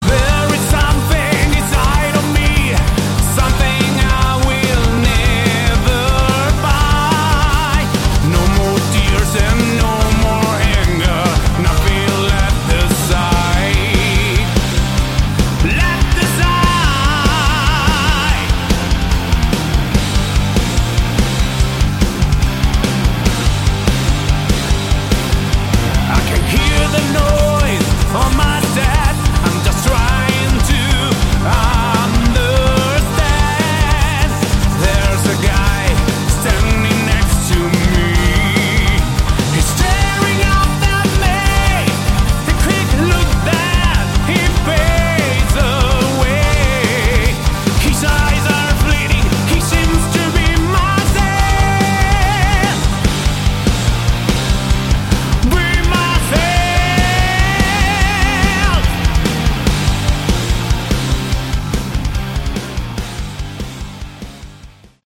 Category: Melodic Prog Rock
vocals
guitars
keyboards
bass
drums